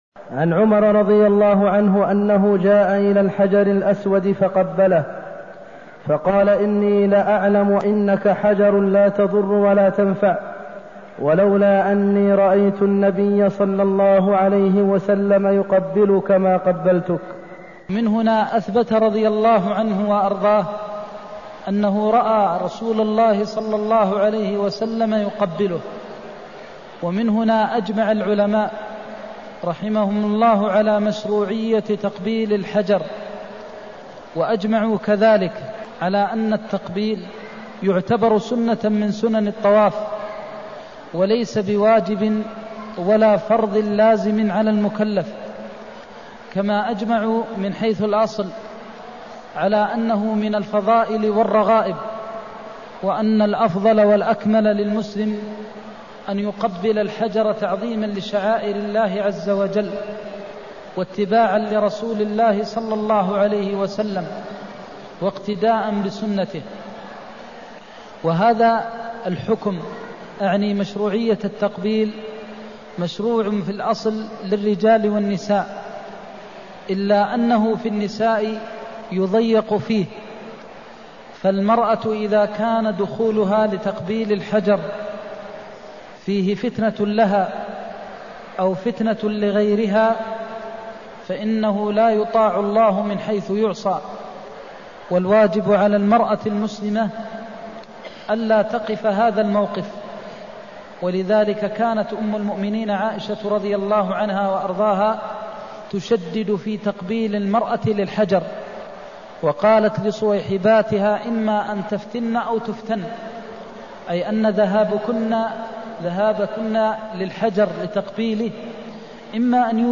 المكان: المسجد النبوي الشيخ: فضيلة الشيخ د. محمد بن محمد المختار فضيلة الشيخ د. محمد بن محمد المختار لولا أني رأيت النبي يقبلك ما قبلتك (215)